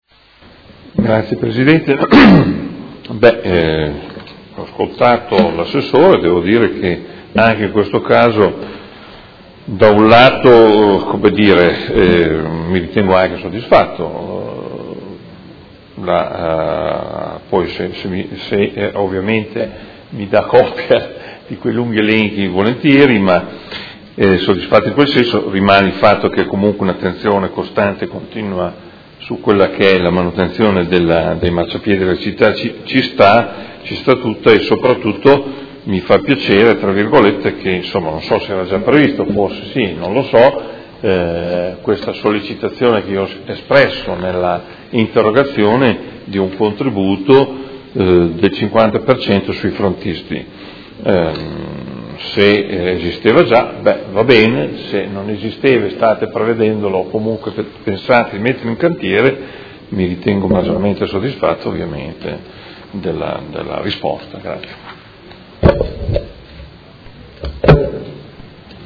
Seduta del 23/03/2017 Replica a risposta Assessore. Interrogazione del Consigliere Morandi (FI) avente per oggetto: Stato di manutenzione delle strade e dei marciapiedi; il Comune deve intervenire.